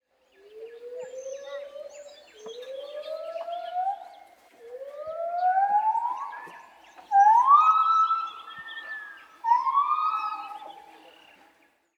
Goudwanggibbon Nomascus gabriellae
Zingende goudwanggibbons
Gibbons kunnen luid ‘zingen’ door hun keelzak op te blazen, die dan fungeert als een klankkast en het geluid nog verder versterkt.
Als dat gelukt is, blijft het paartje hun hele leven samen en zingen ze prachtige duetten.
Het dominante vrouwtje roept met een zogeheten “great call”: een serie aan luide, steeds snellere tonen die wel een kilometer ver door het bos kan reiken.
gibbons_zingen.mp3